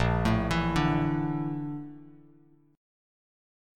A#M#11 chord